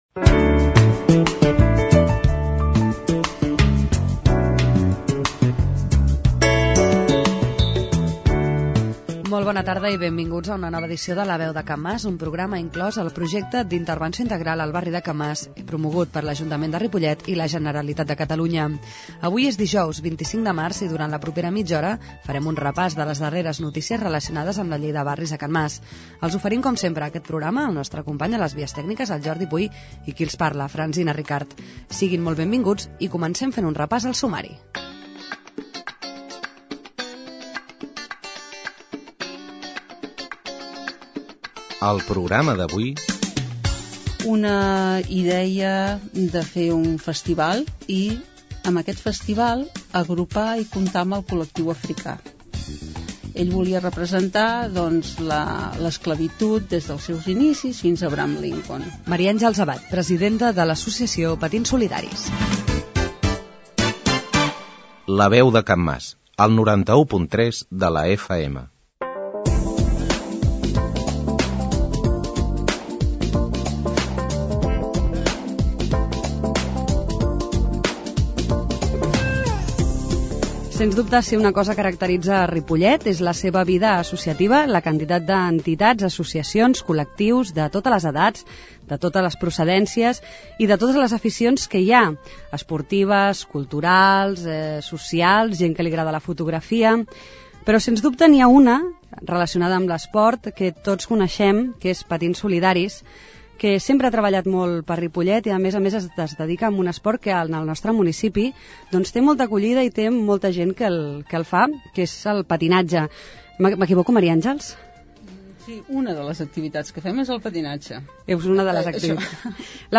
La Veu de Can Mas �s un programa de r�dio incl�s en el Projecte d'Intervenci� Integral al barri de Can Mas, que s'emet el darrer dijous de mes, de 19 a 19.30 hores i en redifusi� diumenge a les 11 del mat�.